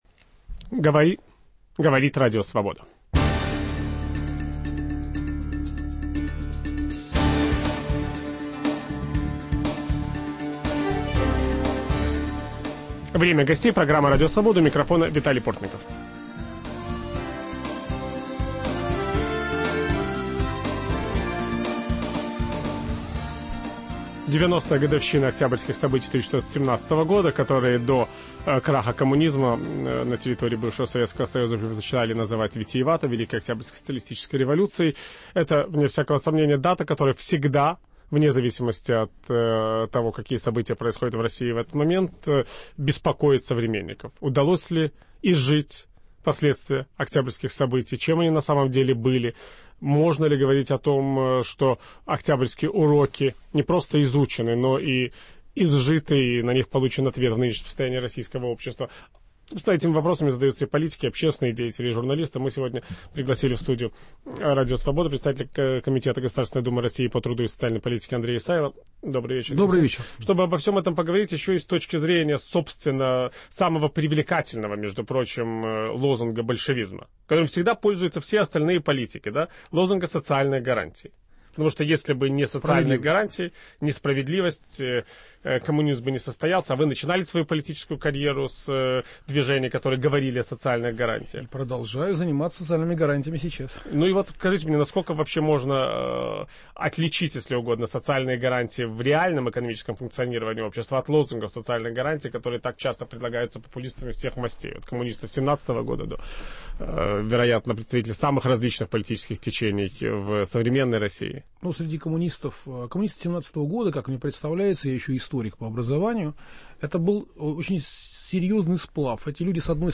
О уроках октябрьских событий для современной России и предвыборной кампании в стране ведущий программы Виталий Портников беседует с заместителем секретаря генерального совета партии "Единая Россия", председателем комитета Государственной Думы РФ по труду и социальной политике Андреем Исаевым.